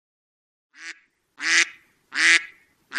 Free Animals sound effect: Duck Quack.
Duck Quack
082_duck_quack.mp3